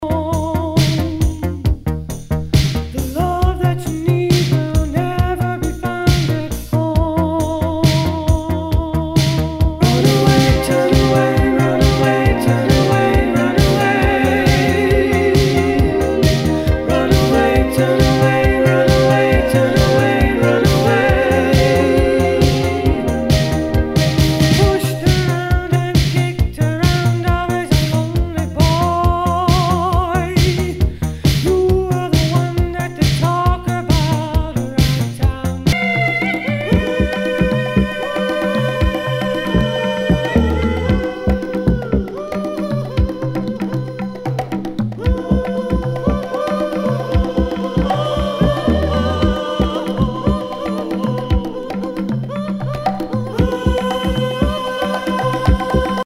SOUL/FUNK/DISCO
ナイス！シンセ・ポップ・ディスコ！